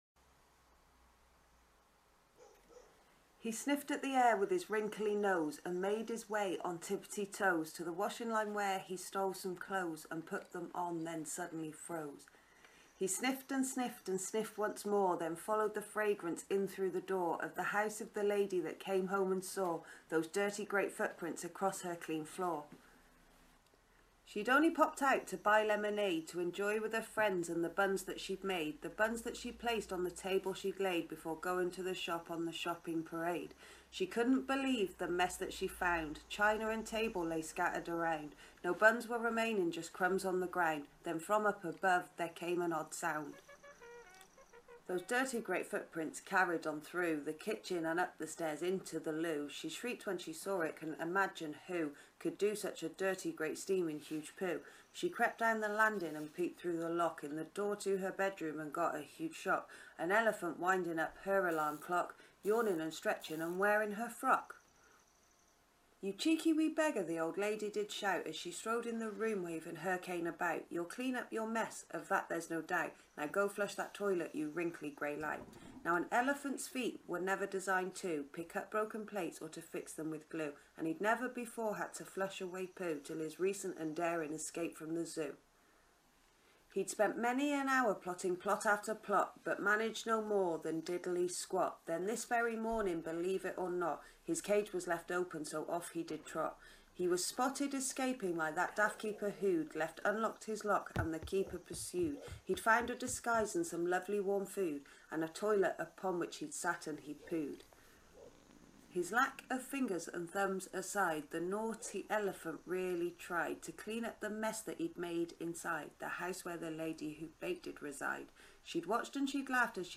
Another of those stories that rhyme.